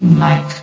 S.P.L.U.R.T-Station-13/sound/vox_fem/mike.ogg
* New & Fixed AI VOX Sound Files